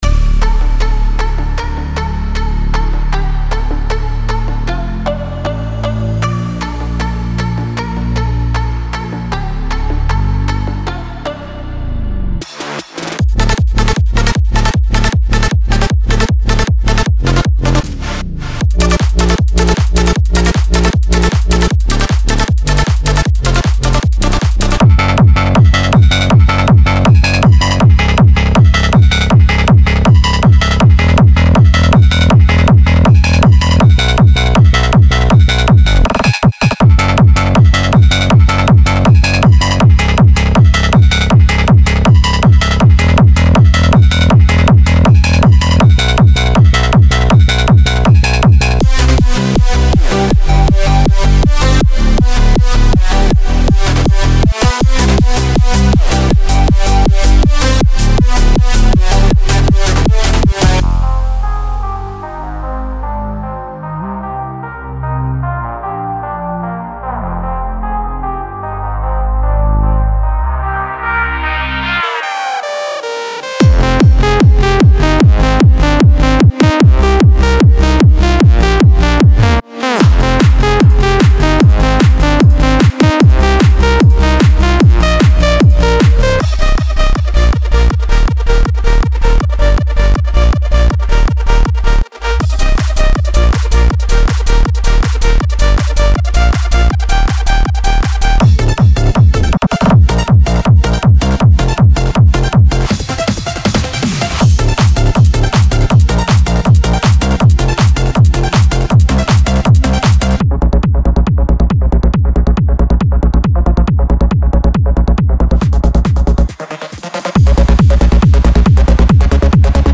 Eurodance Melodic Techno Techno
including hand-crafted leads, basses, plucks and drums.